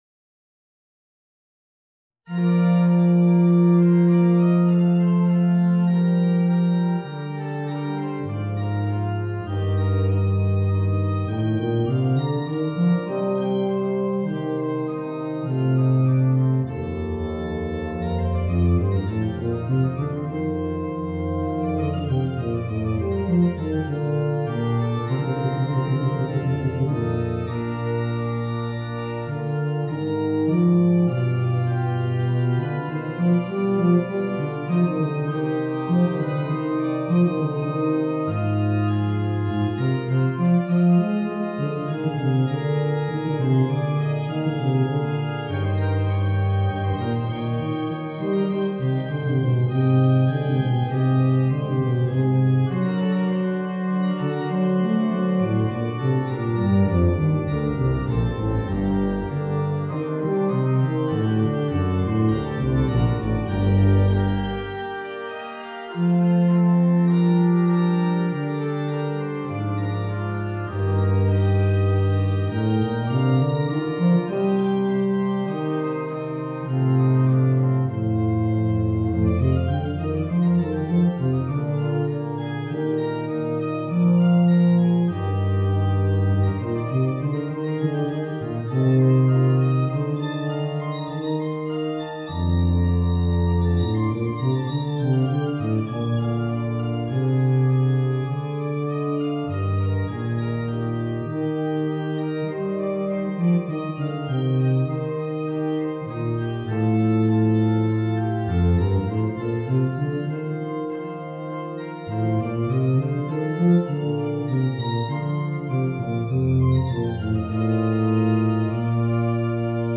Basse & orgue en sibérie